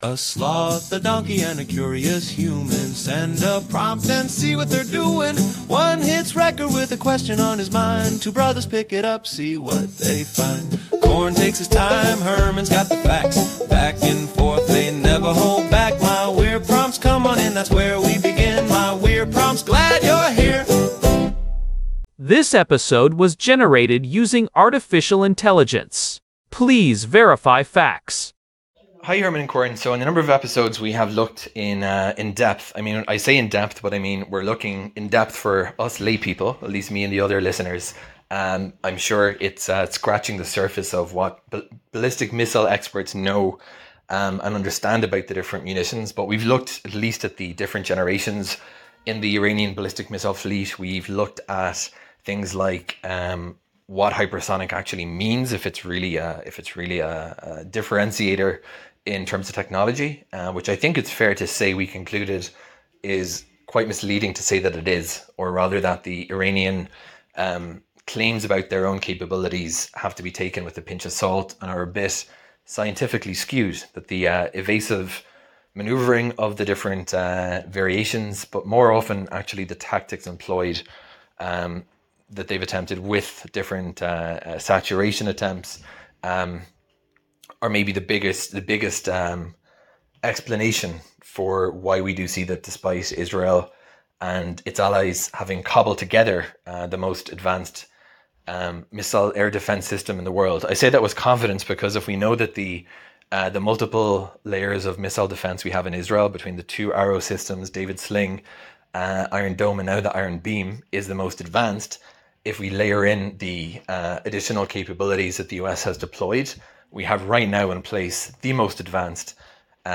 In this episode, recorded amidst the sirens of Jerusalem, we move beyond Iran’s direct capabilities to analyze the "wall of fire" created by its regional proxies: Hezbollah in Lebanon and the Houthis in Yemen.